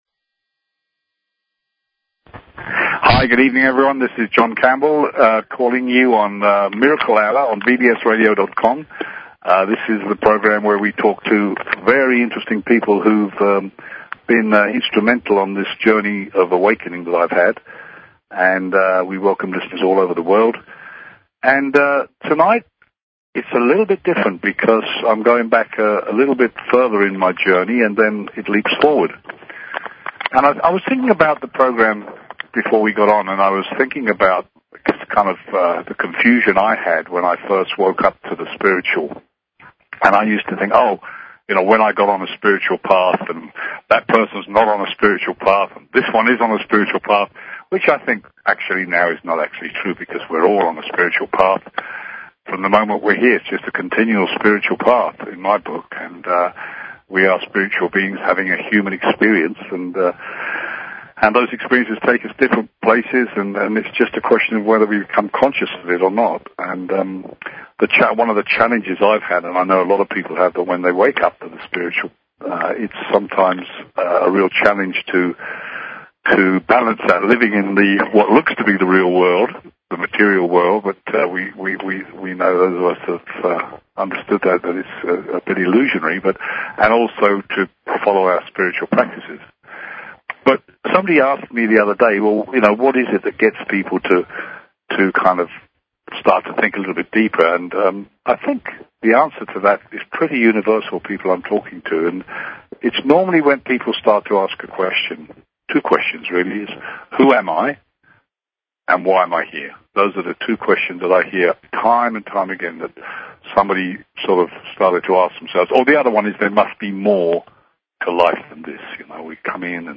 Talk Show Episode, Audio Podcast, Miracle_Hour and Courtesy of BBS Radio on , show guests , about , categorized as